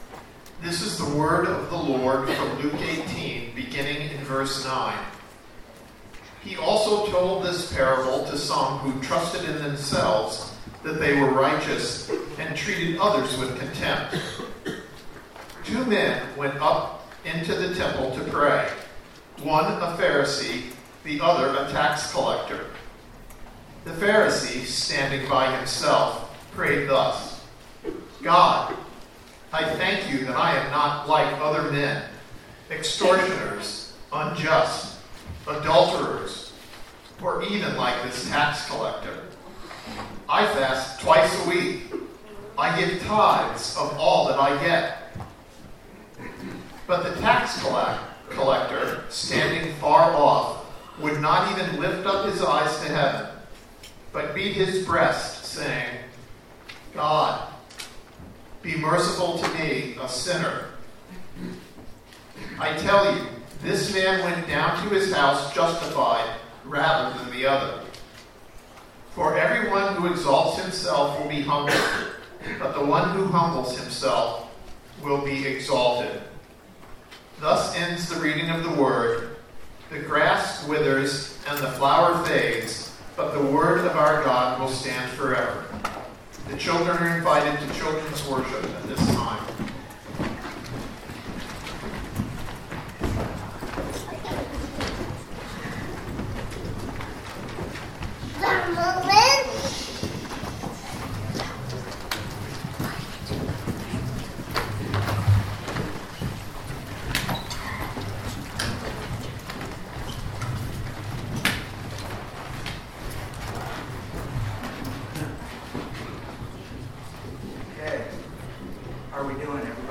Passage: Luke 18:9-14 Service Type: Sunday Morning